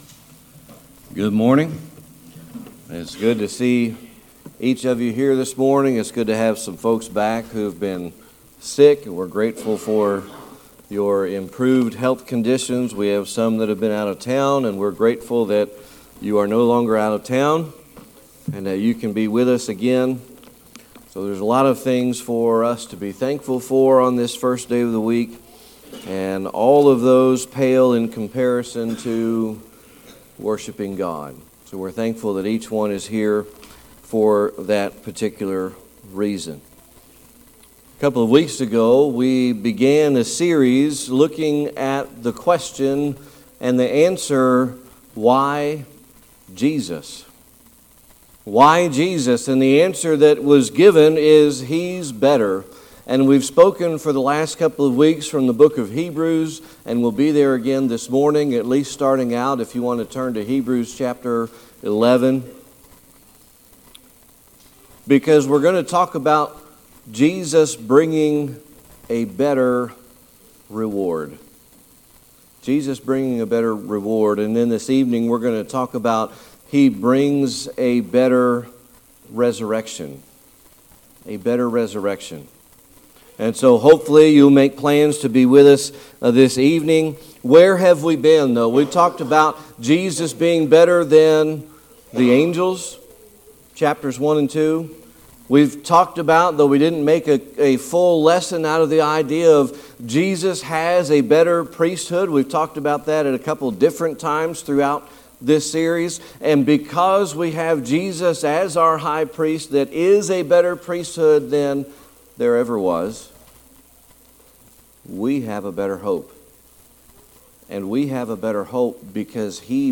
Hebrews 11:16 Service Type: Sunday Morning Worship Recently